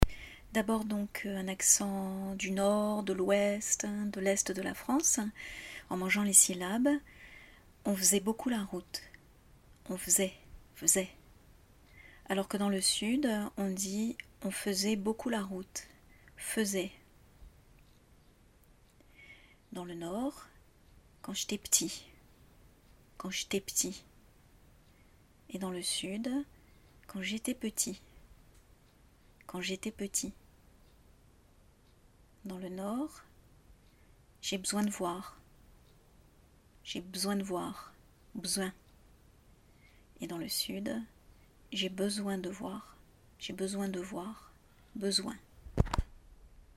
Si vous voulez comparer avec un accent plus « sudiste »: